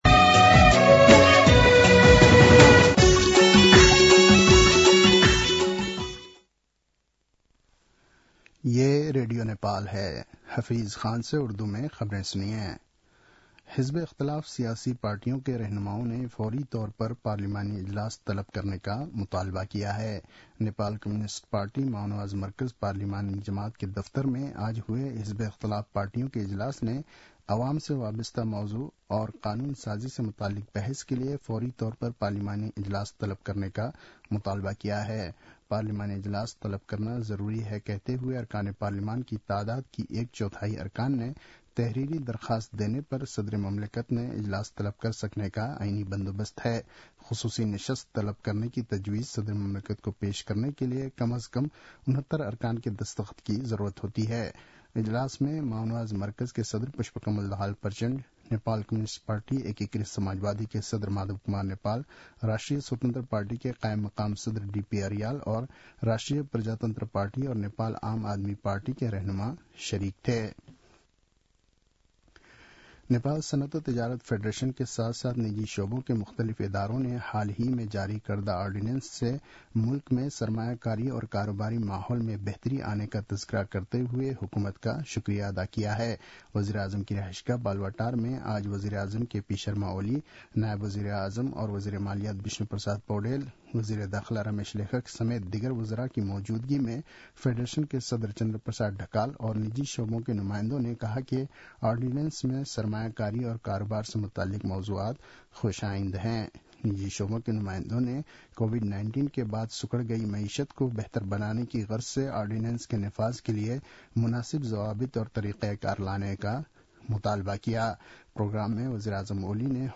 उर्दु भाषामा समाचार : ३ माघ , २०८१
Urdu-News-10-2.mp3